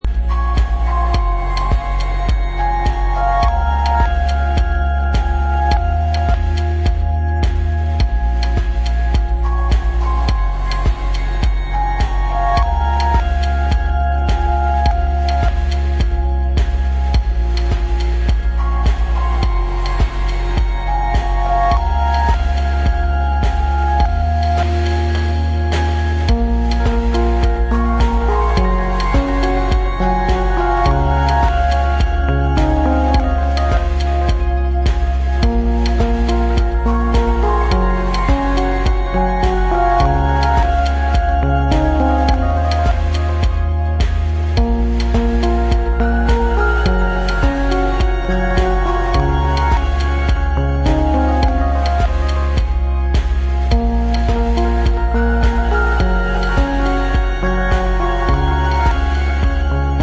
supplier of essential dance music
intense, deep moving sound with melancholic undertones